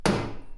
Metal hit
Simple metallic "thunk" sound
metalthunk.mp3